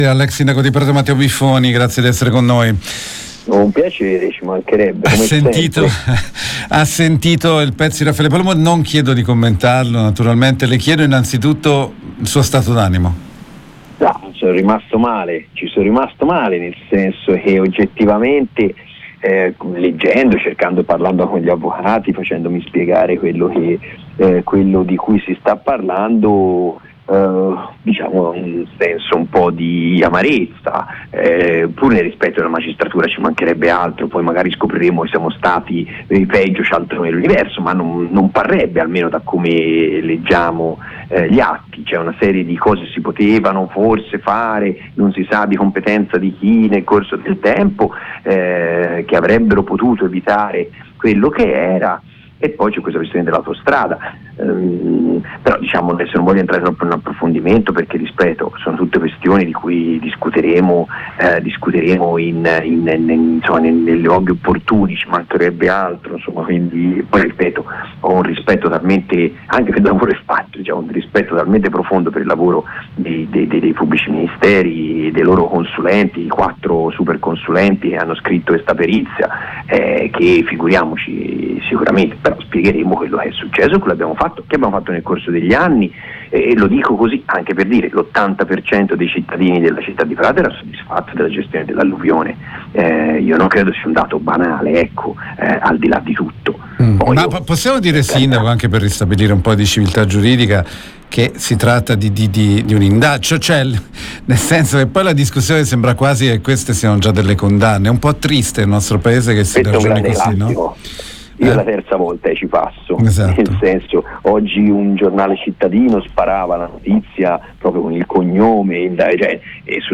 Queste le parole del sindaco di Prato Matteo Biffoni ai nostri microfoni dopo l’avviso di garanzia per omicidio e disastro colposo a seguito dell’alluvione del 2 novembre 2023